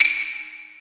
normal-hitwhistle1.ogg